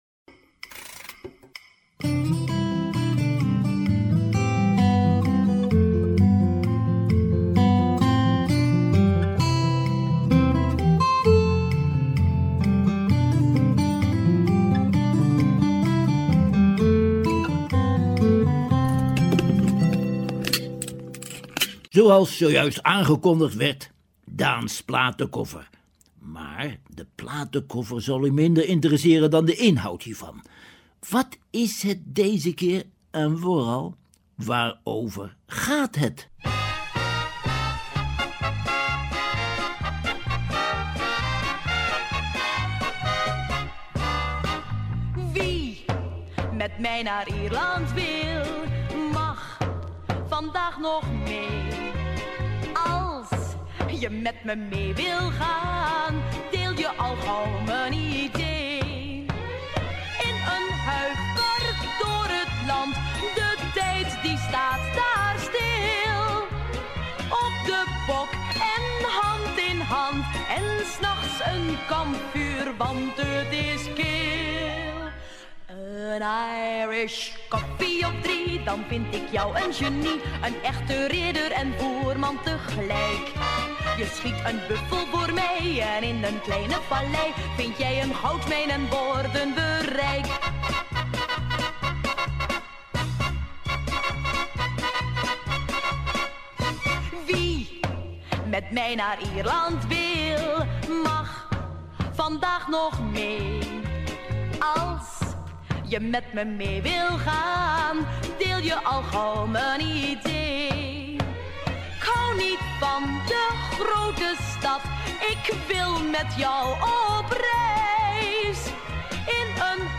Hij doet een greep uit de vele liedjes en melodietjes uit en over Ierland.
We kunnen luisteren naar muziek van de penny-whistle uit het afgelegen Connemara gebied (foto midden).